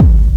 • Big Fat Modular Techno Kick.wav
Big_Fat_Modular_Techno_Kick_zpi.wav